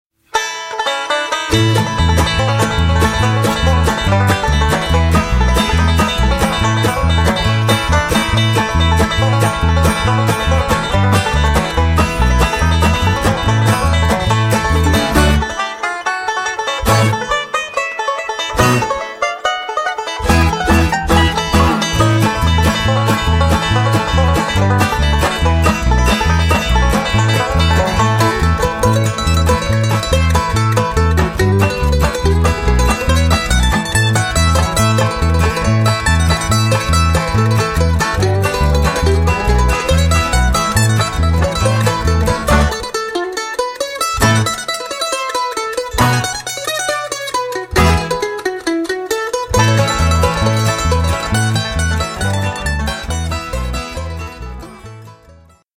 instr. skladba / instr. tune